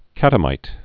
(kătə-mīt)